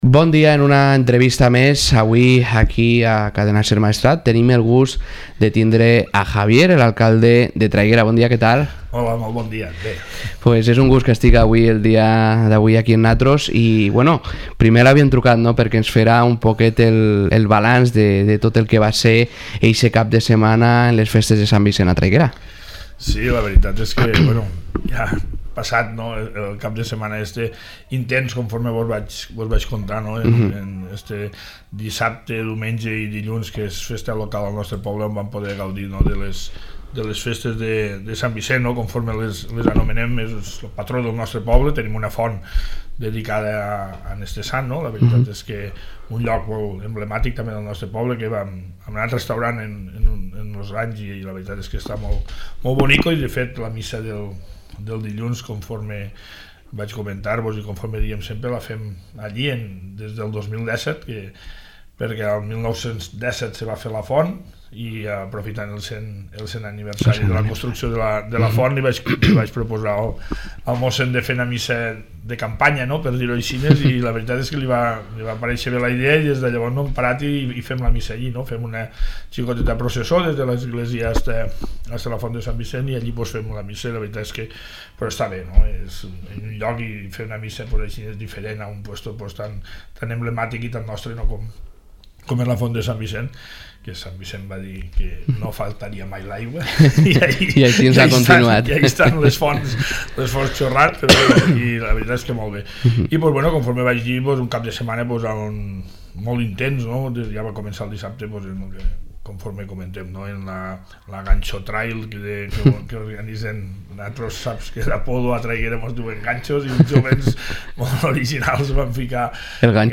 Podcast | Entrevista a Javier Ferrer alcalde de Traiguera